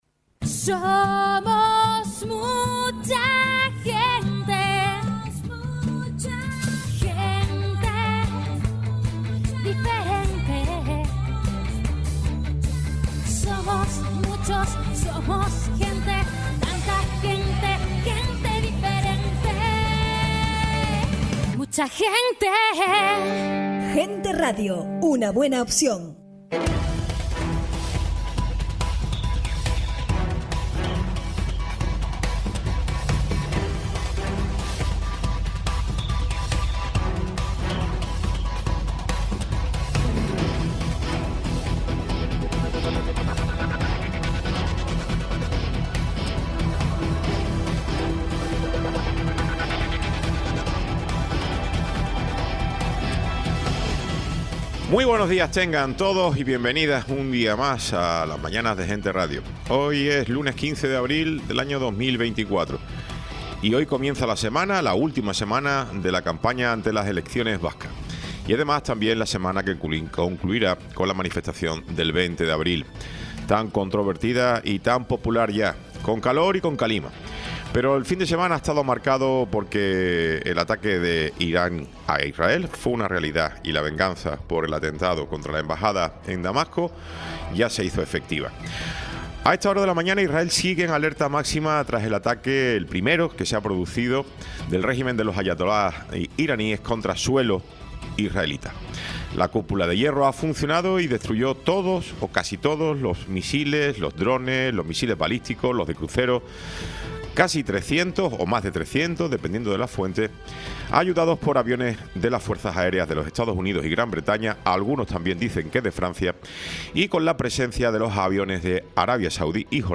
Tertulia
Programa sin cortes